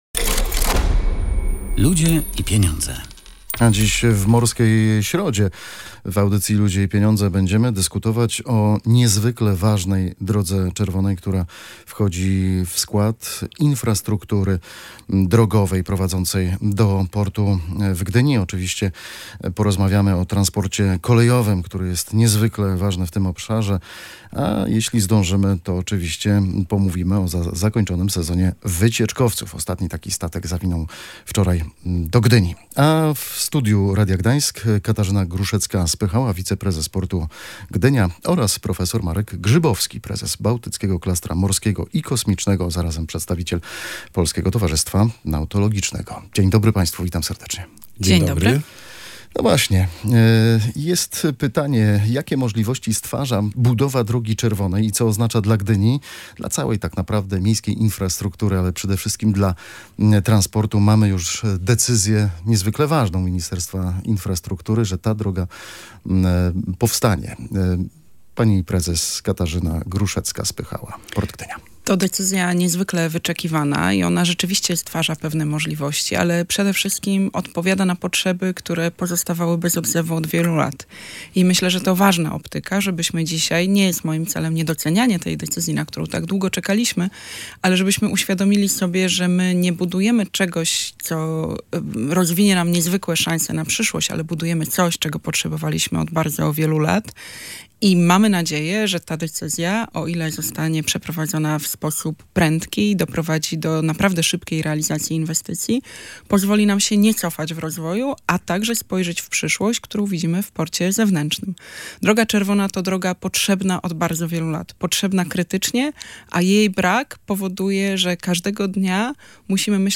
Zamknięcie Estakady Kwiatkowskiego w Gdyni lub ograniczenie ruchu ciężarowego na tej trasie spowodowałoby potężne straty finansowe dla Portu Gdynia – podkreślali goście audycji „Ludzie i Pieniądze”.